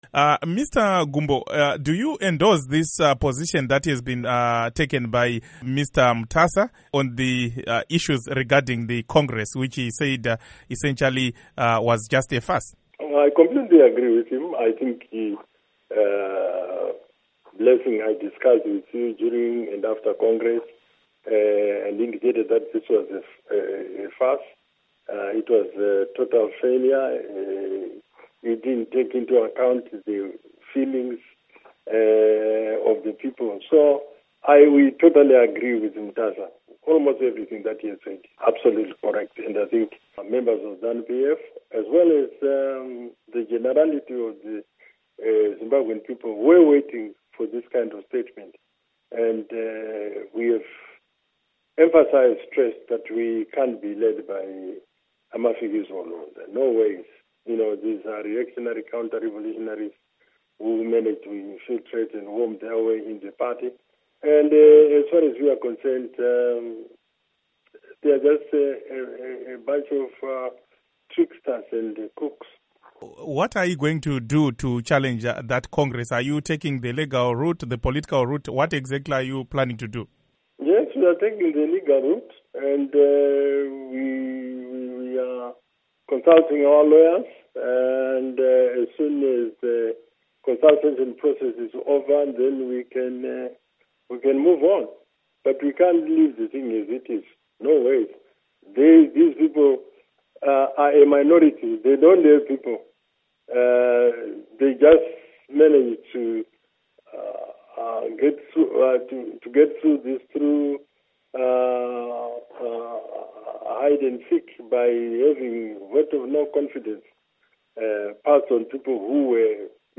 Exclusive Interview With Rugare Gumbo